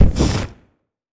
fireball_impact_burn_04.wav